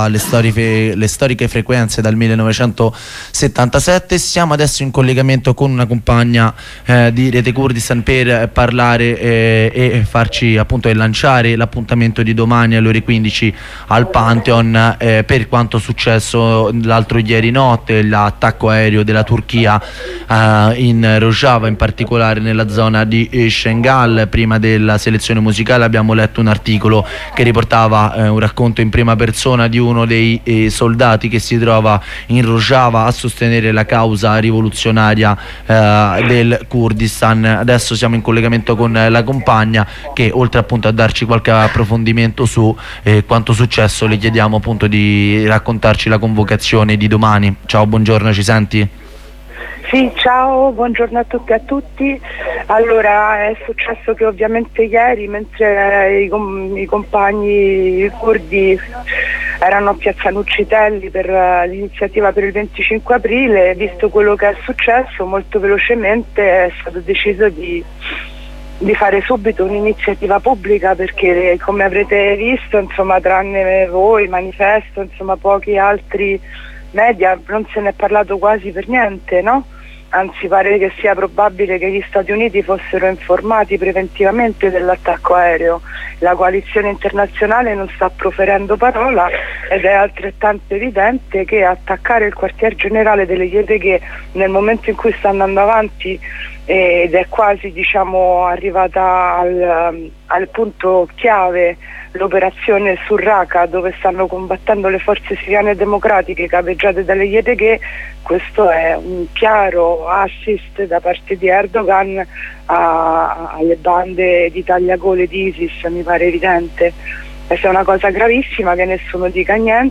Corrispondenza con uno dei compagni colpiti dalla pericolosità sociale